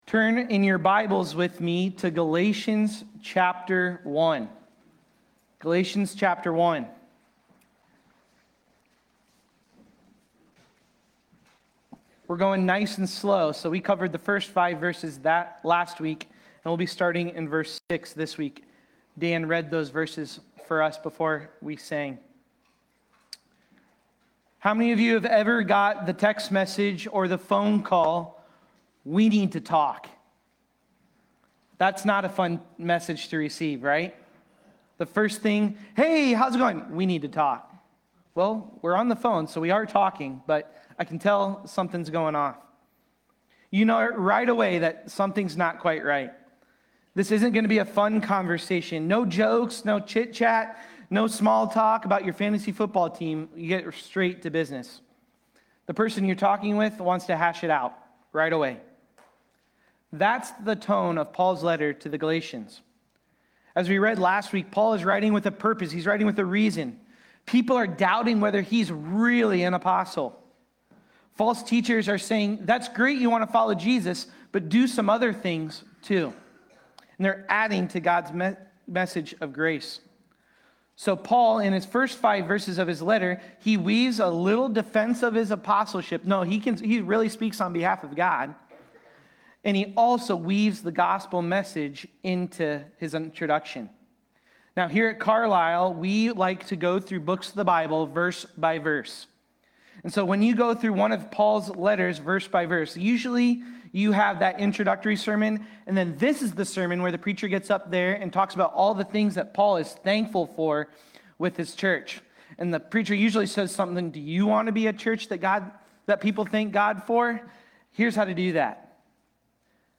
Galatians-1.6-10-Sermon-Audio.mp3